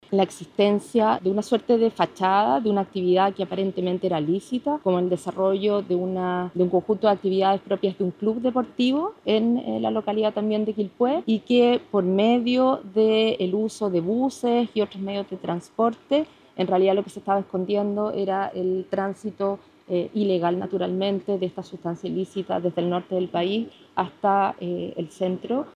La fiscal regional de Valparaíso, Claudia Perivancich, informó que la organización usaba de “fachada” un club deportivo y que, por medio del uso de buses, se escondía el tránsito ilegal de las sustancias ilícitas desde el norte del país a Quilpué.